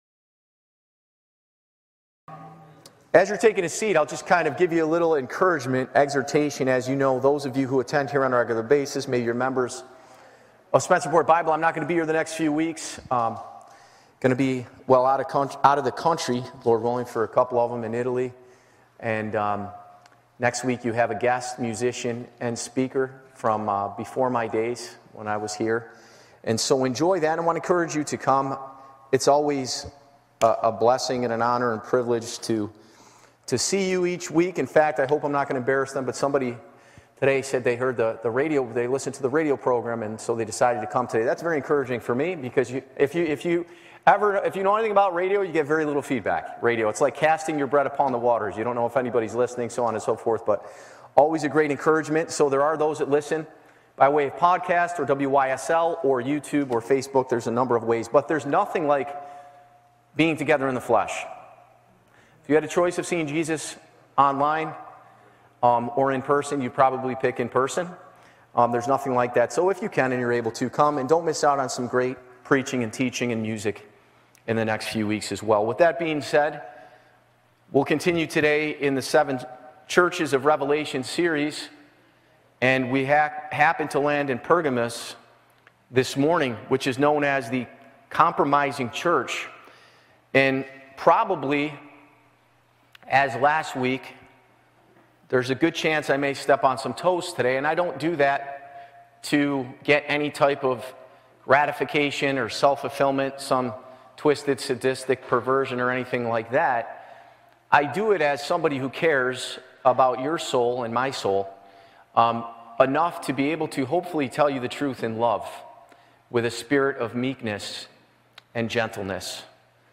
Live Recording